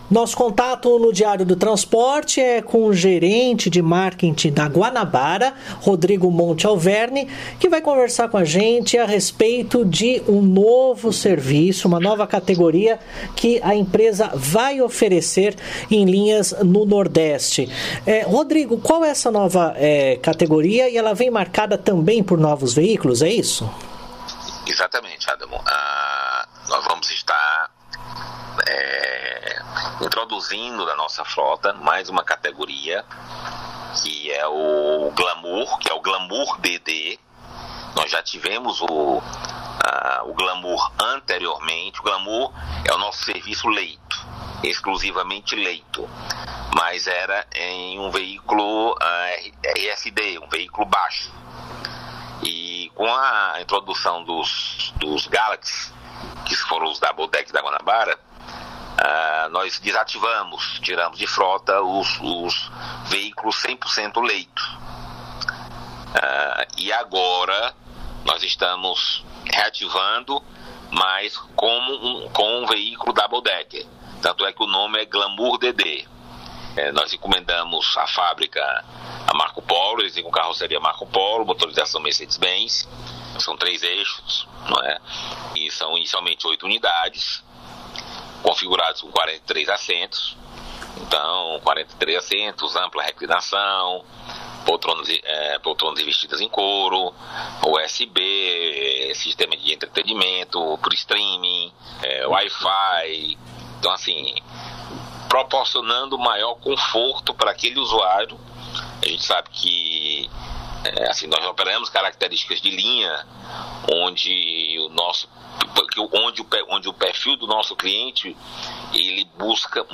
ENTREVISTA: Guanabara cria nova categoria de serviços e oito novos ônibus de dois andares entram em operação até o final de setembro